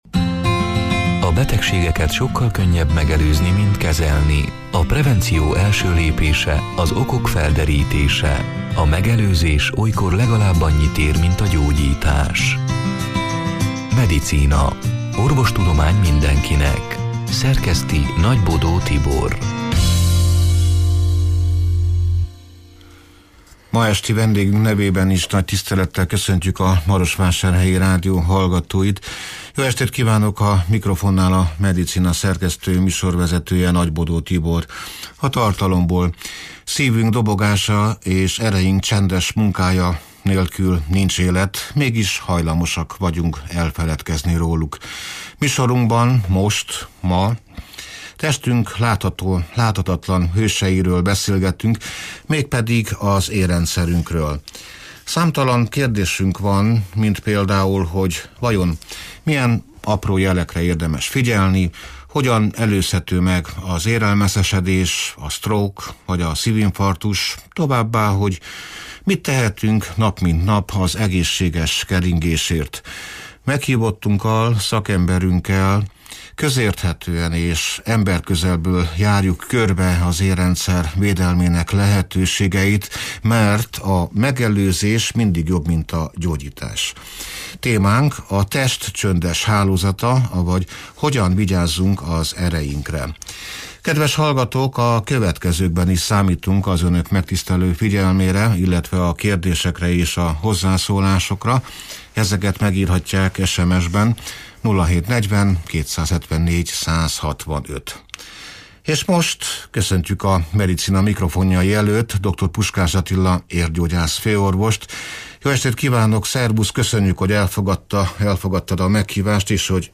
(2025. július 9-én, szerdán este nyolc órától élőben)